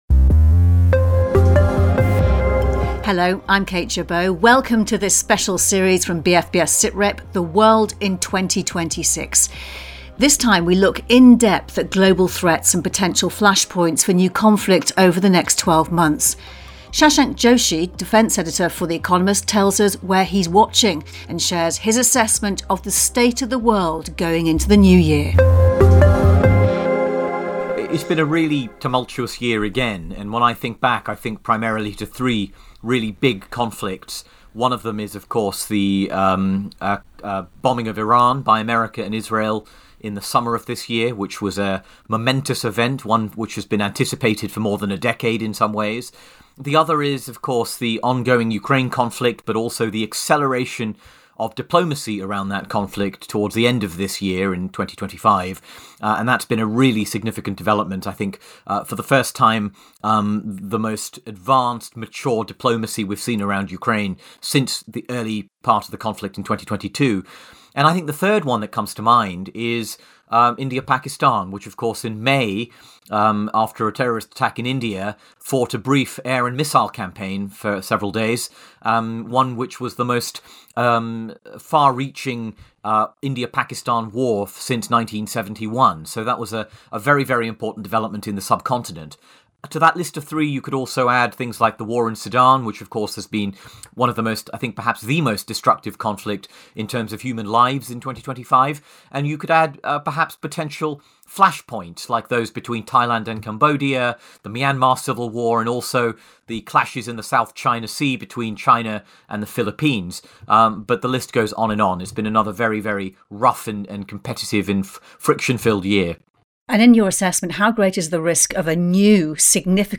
1 ABL DUOs: Judge Penny Brown Reynolds/Shirley Franklin 32:55 Play Pause 17h ago 32:55 Play Pause Play later Play later Lists Like Liked 32:55 Send us a text This episode of the Atlanta Business League’s TELLING OUR STORY podcast was recorded in front of a live audience.